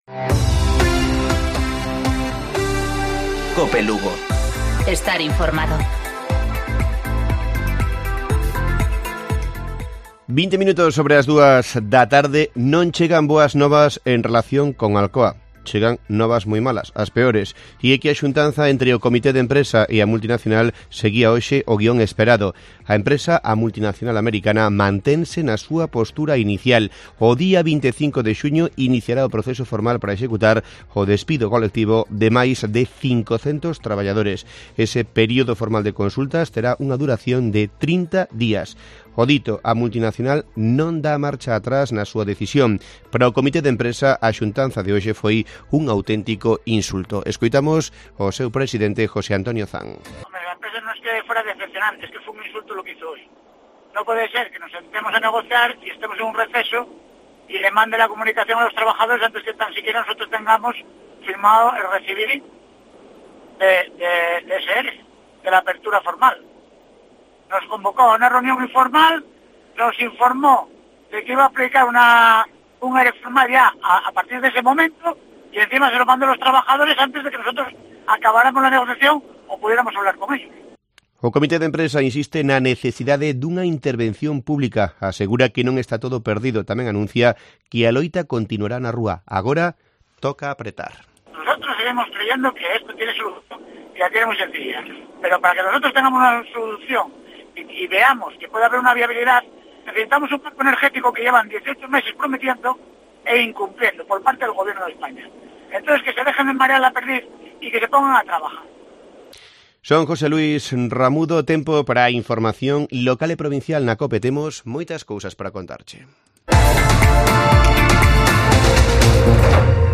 Informativo Mediodía Cope.